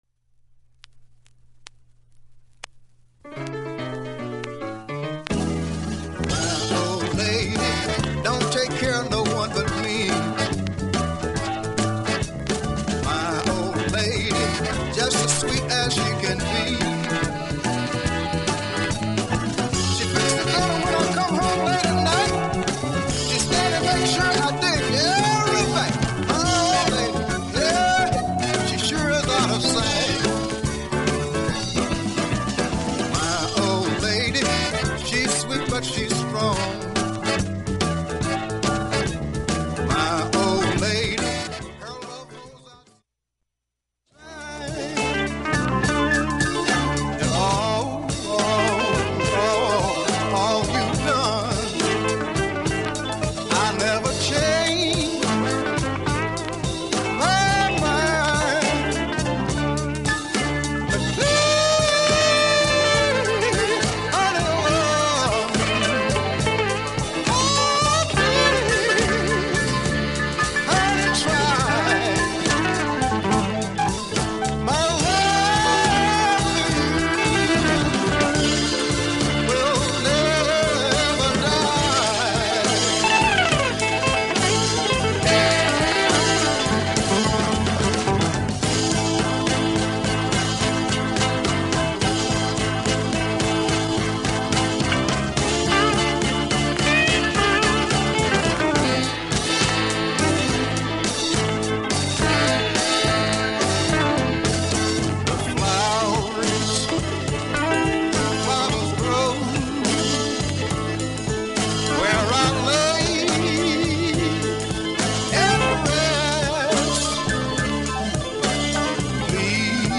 音質目安にどうぞ ほかはおおむね問題なし良い音質です。
オンリーのモノラル盤